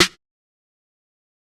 Snare 8.wav